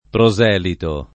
pro@$lito] s. m. — usato quasi soltanto nel pl. proseliti — di qui le incertezze sulla forma da dare al sing., e le sporadiche apparizioni, oggi come in passato, delle varianti proselita [pro@$lita], esatta per il femm. (es.: Te proselita illustre ai grandi arcani Inizierò [t% ppro@$lita ill2Stre ai gr#ndi ark#ni iniZZLer0], Casti) ma non giustificata per il masch., e proselite, non giustificata in nessun caso